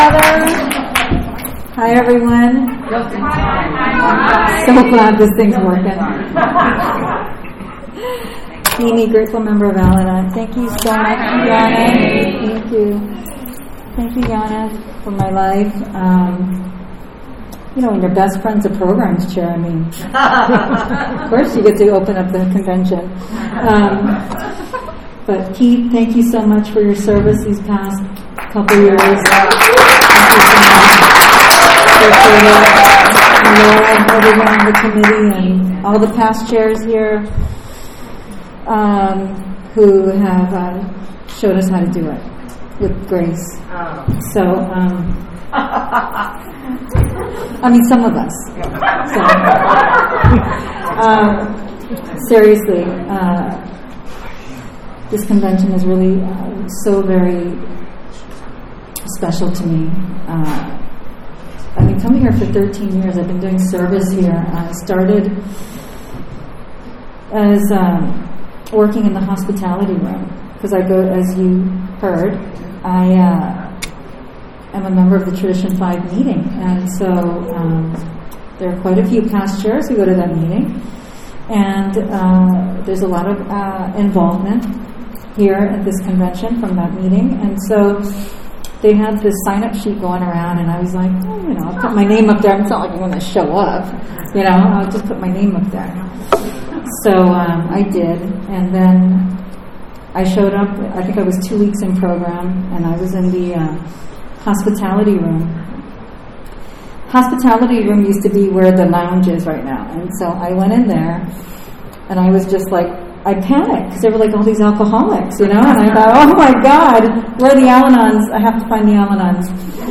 47th Annual San Fernando Valley AA Convention
Opening Al-Anon Meeting &#8211